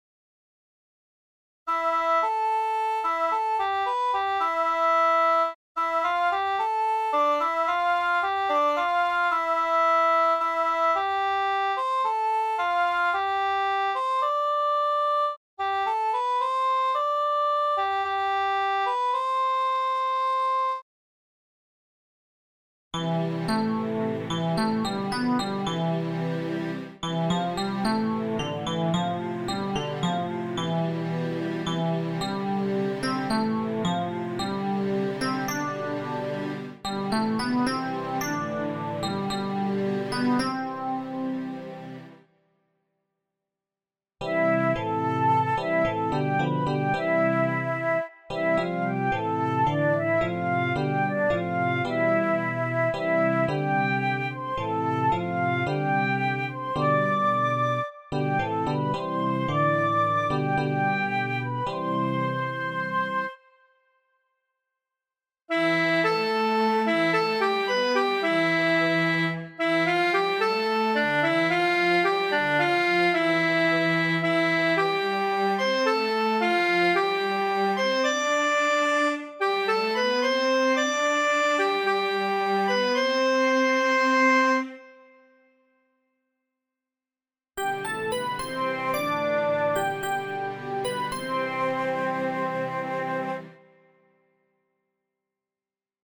Keyboard C-Dur